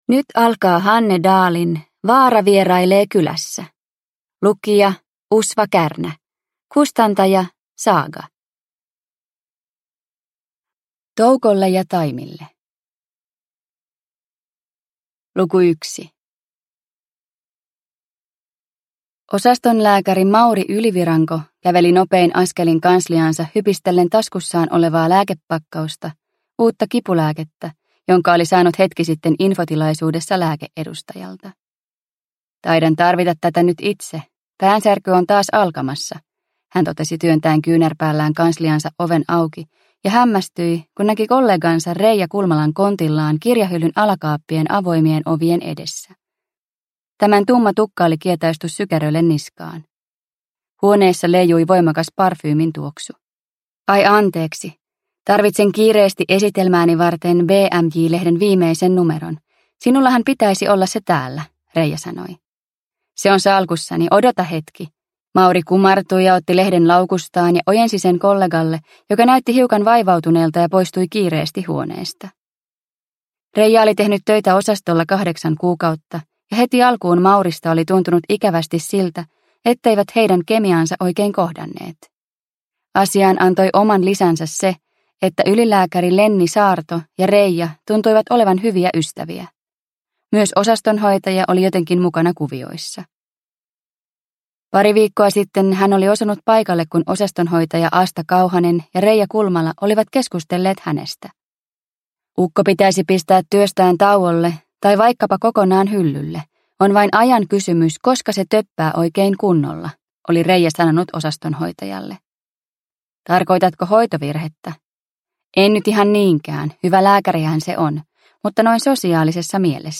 Vaara vierailee kylässä (ljudbok) av Hanne Dahl